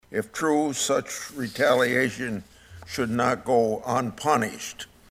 IOWA SENATOR CHUCK GRASSLEY TALKED ABOUT THE ALLEDGED FRAUD IN MINNESOTA WEDNESDAY DURING A HEARING IN THE SENATE JUDICIARY COMMITTEE.